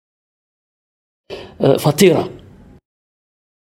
uitspraak Fatira uitspraak Fatirat (au pluriel) uitspraak uitleg Un grand pain, mais il n'y a pas beaucoup de grammes dedans.